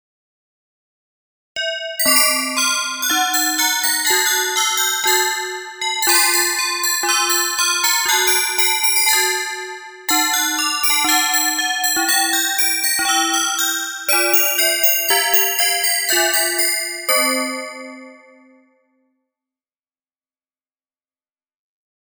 Das Instrument besteht aus einem in spitzem Winkel aufzuklappenden Gestell, an dem Glocken hängen.
Die Beine übernehmen dabei die Bassstimme der untersten Glockenreihe, die meist nur wenige Töne umfasst.
Klangprobe einer Vaazet